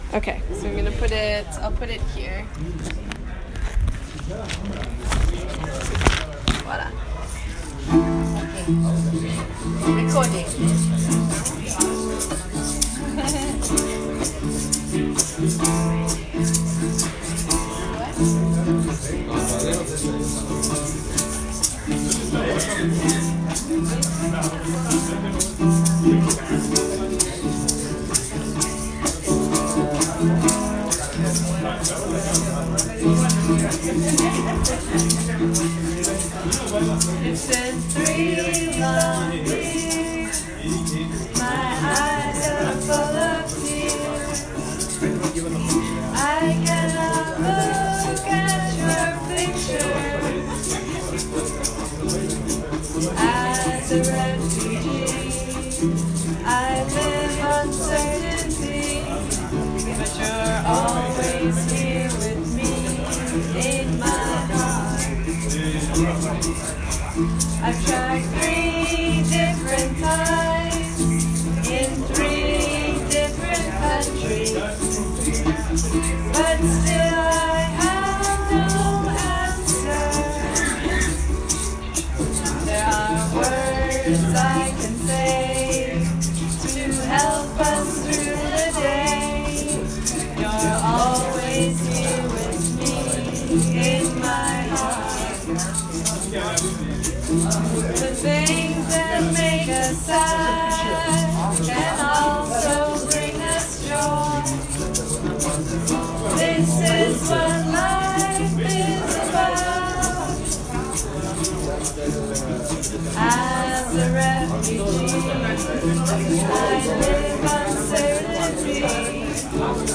We wrote this song on a grey, brisk, and windy Monday afternoon at the Fedasil Arrival Center in Brussels, Belgium. The beginning lyrics were spoken words from a father about the pain of being separated from his children for three years as he searches for a country in the EU that will grant him asylum so he can bring his family to safety.
We wrote and recorded the song and asked another resident if they could share it with him.